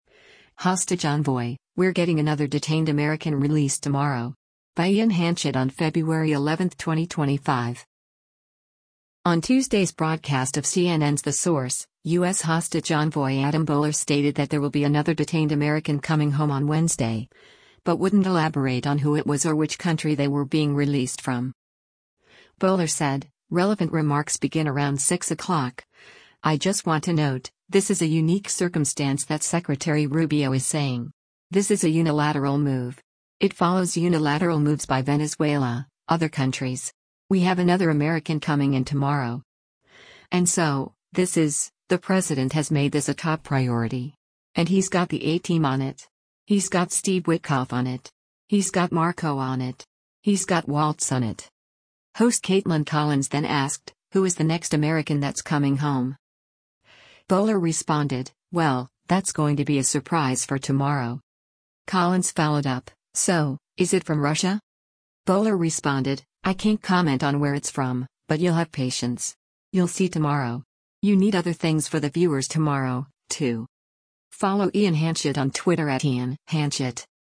On Tuesday’s broadcast of CNN’s “The Source,” U.S. Hostage Envoy Adam Boehler stated that there will be another detained American coming home on Wednesday, but wouldn’t elaborate on who it was or which country they were being released from.
Host Kaitlan Collins then asked, “Who is the next American that’s coming home?”